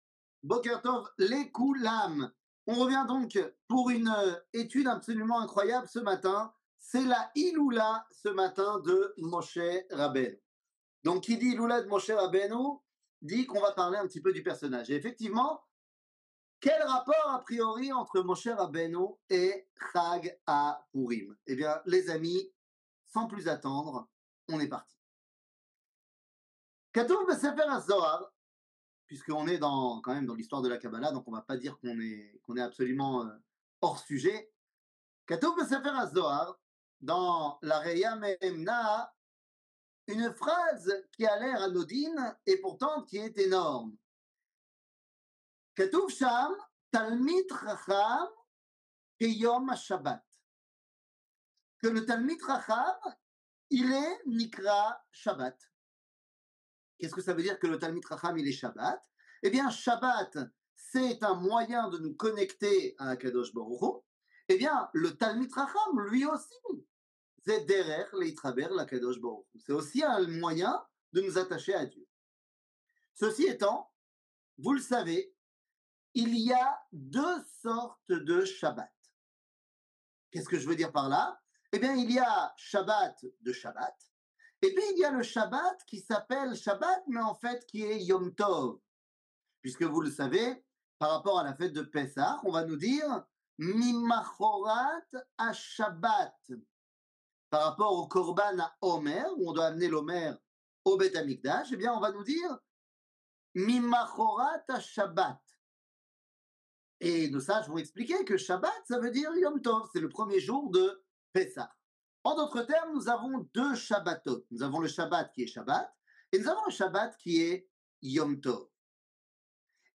Pourim, la brit mila de Moshe Rabbenou 00:53:28 Pourim, la brit mila de Moshe Rabbenou שיעור מ 17 מרץ 2024 53MIN הורדה בקובץ אודיו MP3 (48.95 Mo) הורדה בקובץ וידאו MP4 (113.91 Mo) TAGS : שיעורים קצרים